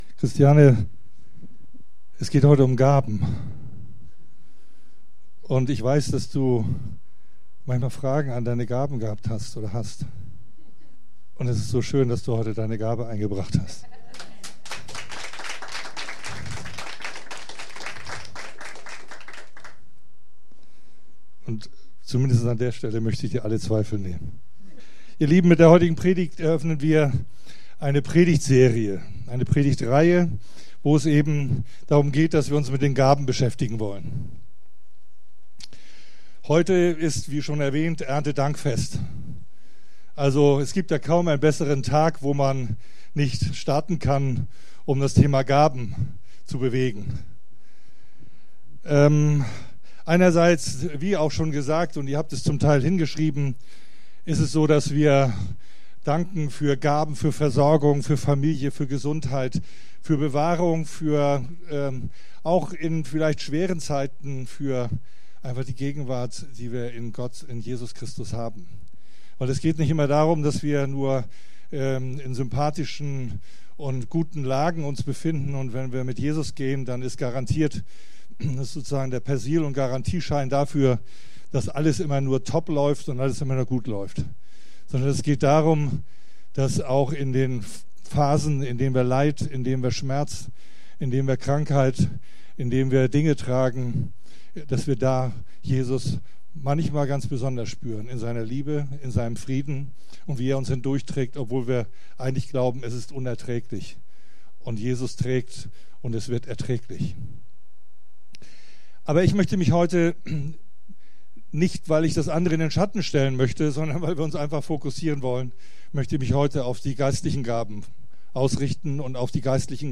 Geistliche Gaben Gottesdienst: Sonntag « Die Menora